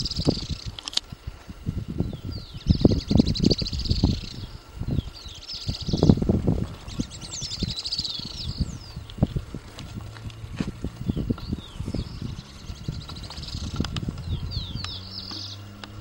Streak-backed Canastero (Asthenes wyatti)
Location or protected area: Camino a El Infiernilllo
Condition: Wild
Certainty: Recorded vocal
espartillero-serranomp3.mp3